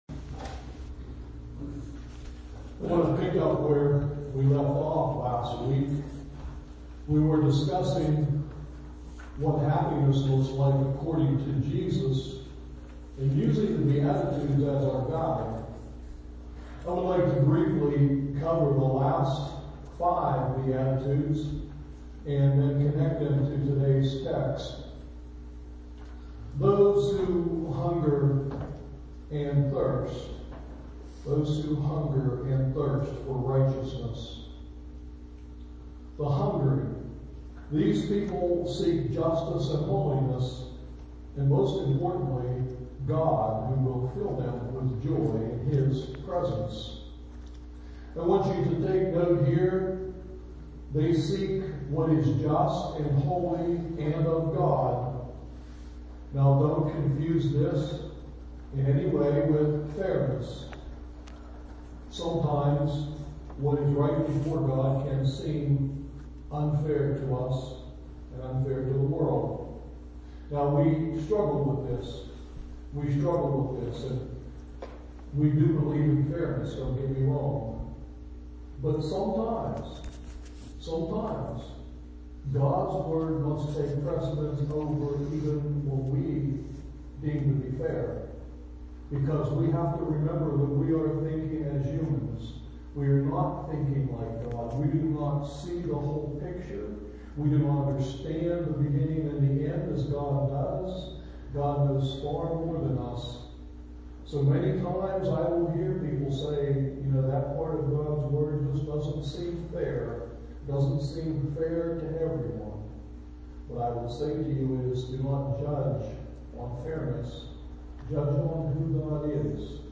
SERMON TEXT: Matthew 5:6-16